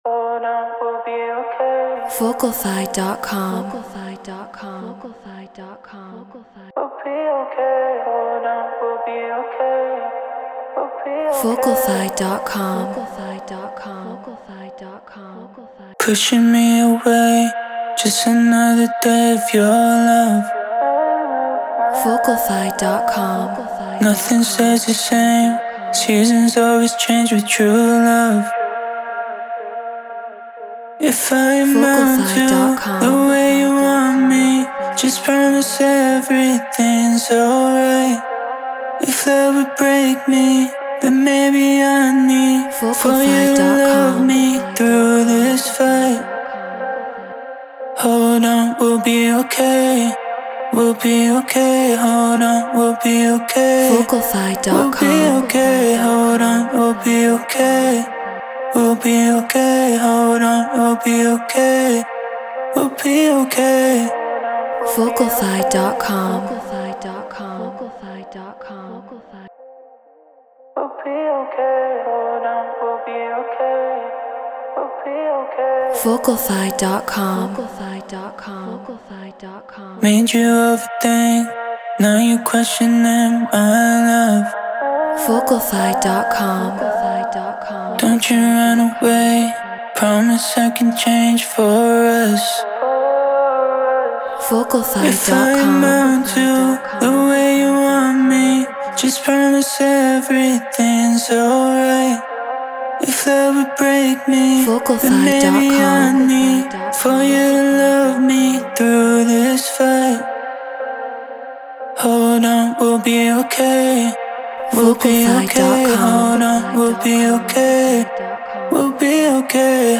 Deep House 120 BPM G#maj
Human-Made